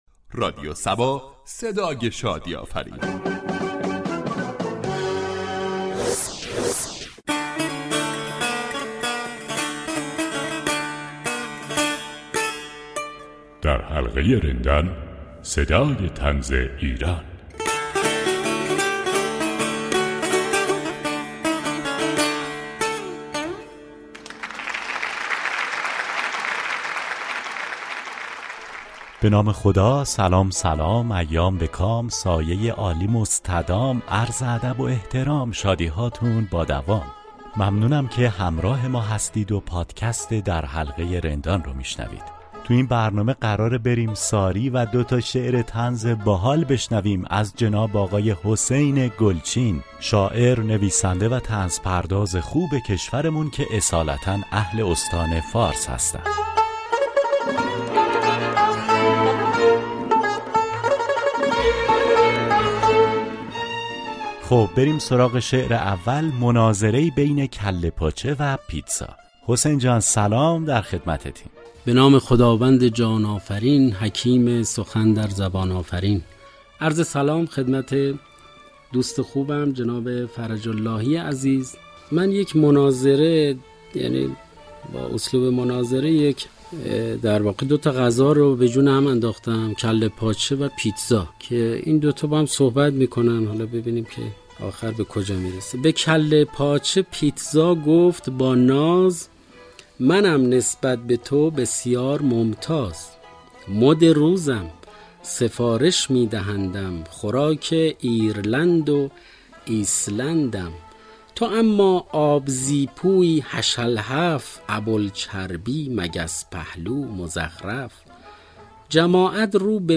دو شعر طنز می‌خواند